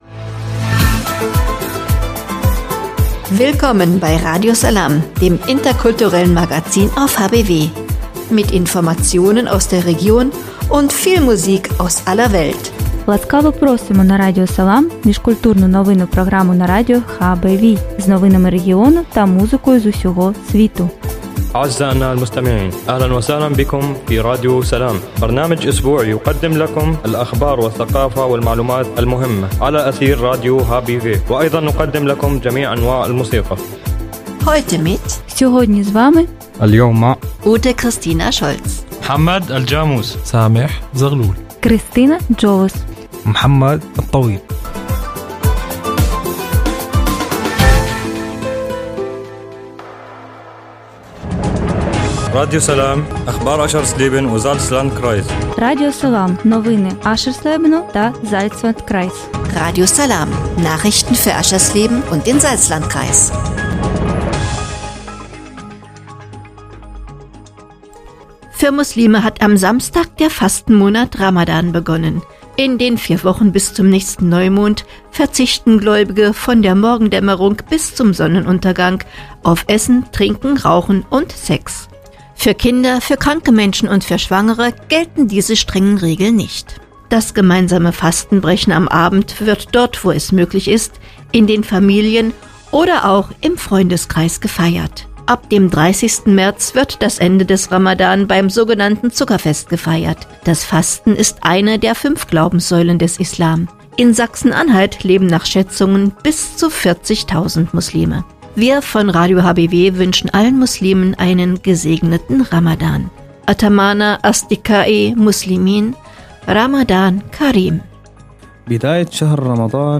„Radio Salām“ heißt das interkulturelle Magazin auf radio hbw. Mit dem Wochenmagazin wollen die Macher alte und neue Nachbarn erreichen: diejenigen, die schon lange in Harz und Börde zu Hause sind, und ebenso Geflüchtete, beispielsweise aus Syrien.